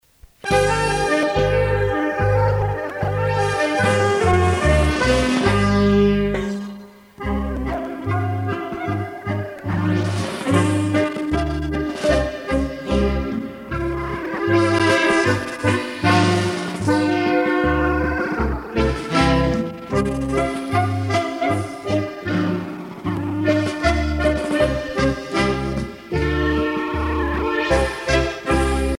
danse : paskovia
Pièce musicale éditée